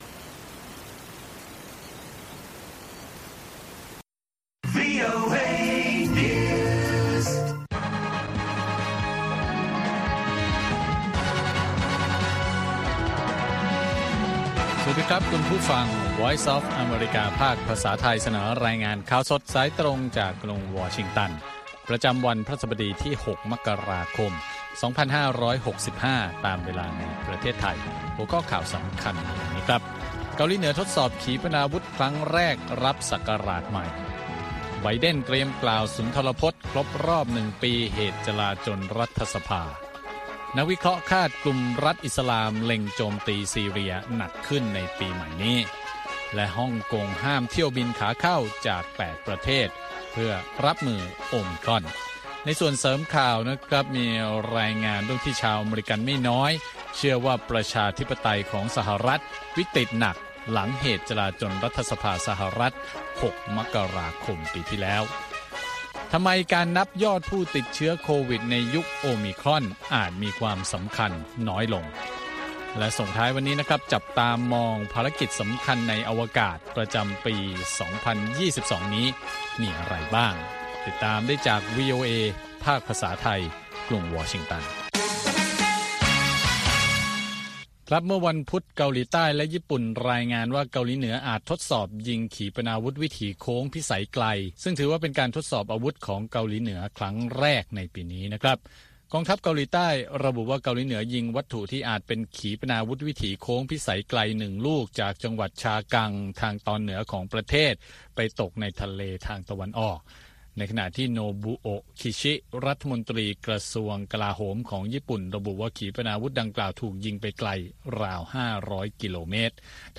คุยข่าวกับ VOA Thai • เกาหลีเหนือทดสอบขีปนาวุธครั้งแรกรับศักราชใหม่ • ฮ่องกงห้ามเที่ยวบินขาเข้าจาก 8 ประเทศ รับมือ “โอมิครอน”